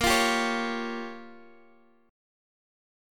A#m6 chord